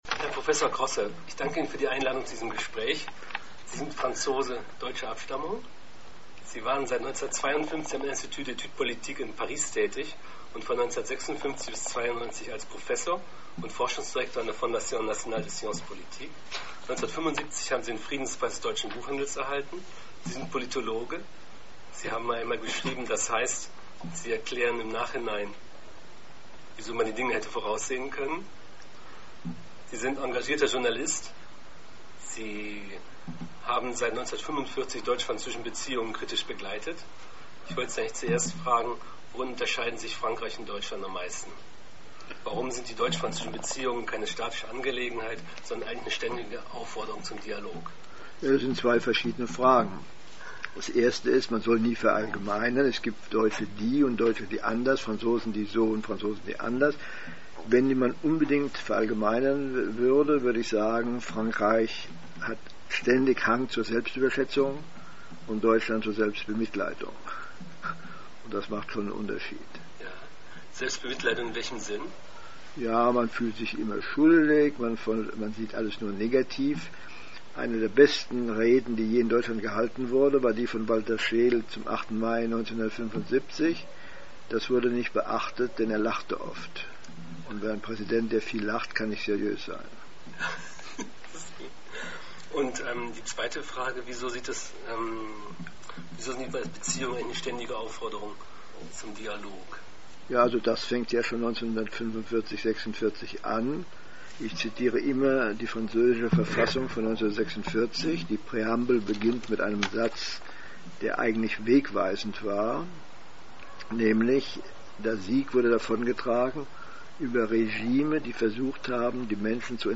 Ein Gespräch mit Alfred Grosser - Das Frankreich-Blog – Relations franco-allemandes -Deutsch-französische Beziehungen - France blog - Literatur, Geschichte, Politik
In Paris hat Alfred Grosser unsere Redaktion am Mittwoch, 18. Juni 2008, zu einem Gespräch empfangen.